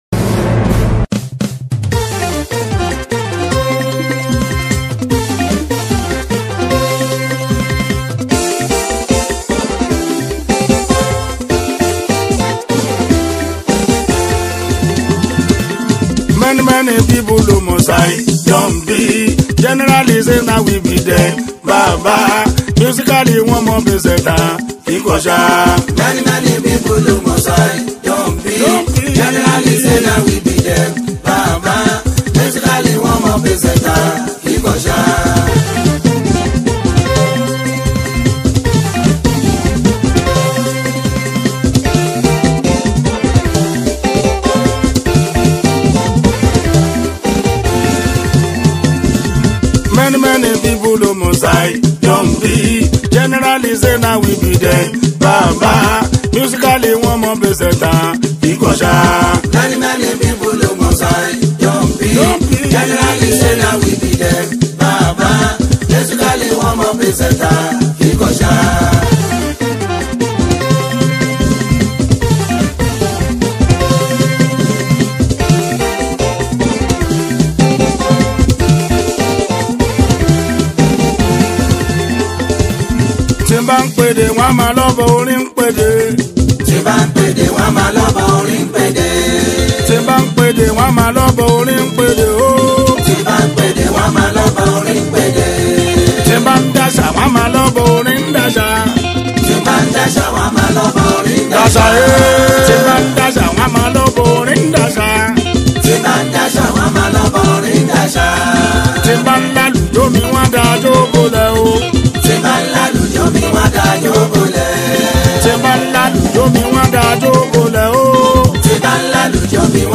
The National YORUBA fuji singer